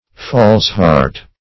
\False"-heart`\